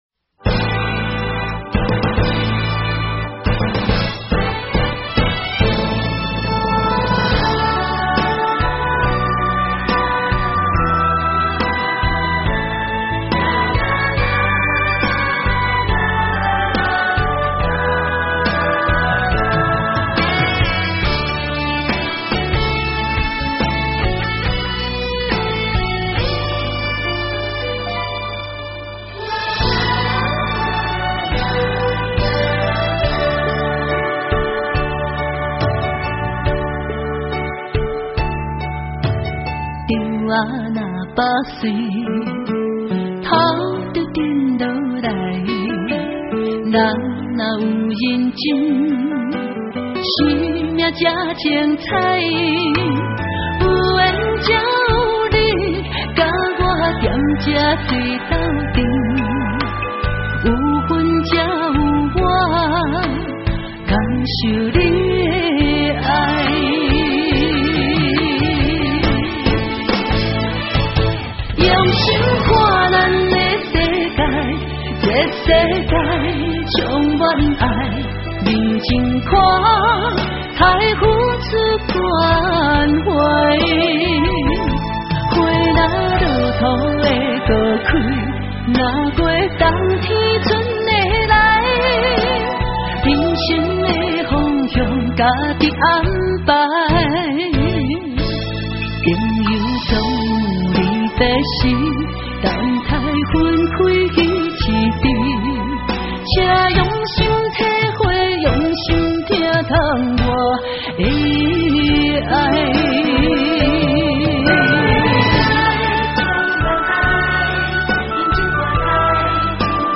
【音樂類型】：華語台語